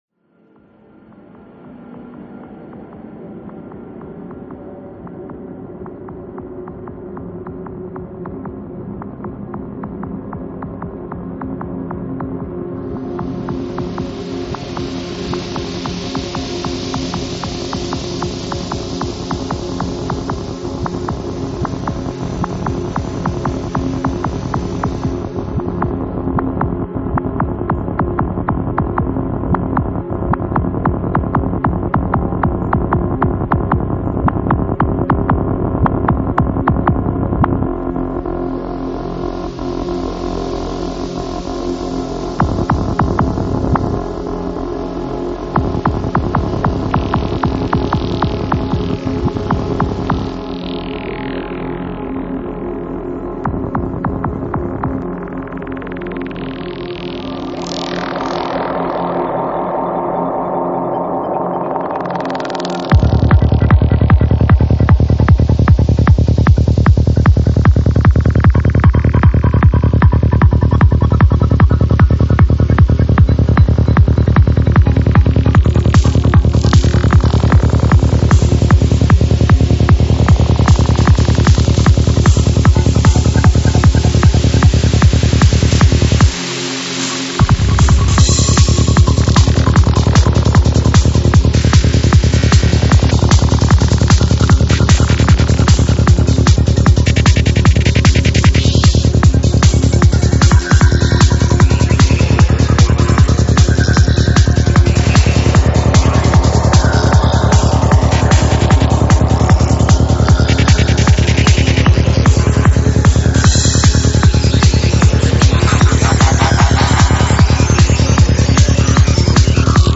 Стиль: Dark Psytrance